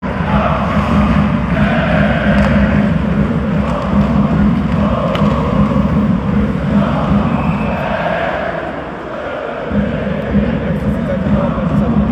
Wie bei nahezu jeder Teamsportart dürfen auch beim Eishockey die Fangesänge nicht fehlen. Wir haben euch die beliebtesten aus dieser Saison zusammengestellt.